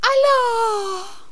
princess_die4.wav